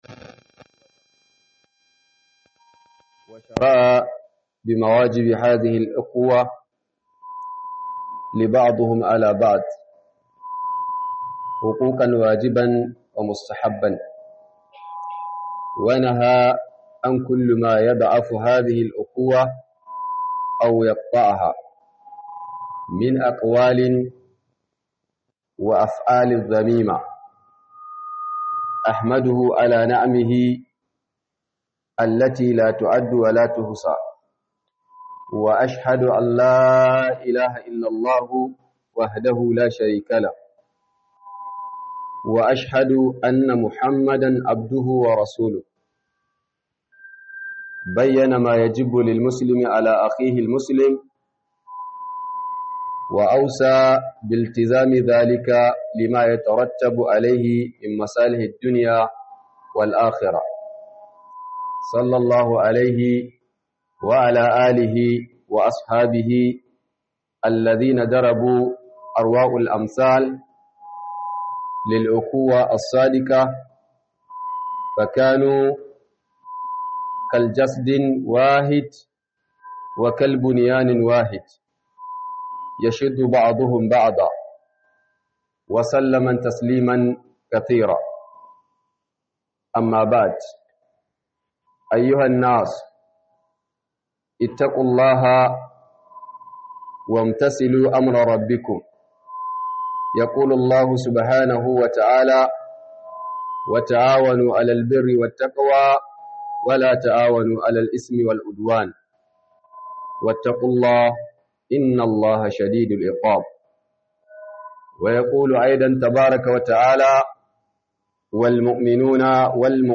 Khudubar Jibwis Low-cost Ningi - Yan-uwantaka a musulunci - Khudubar Sallar Juma'a by JIBWIS Ningi
Khudubar Jibwis Low-cost Ningi - Yan-uwantaka a musulunci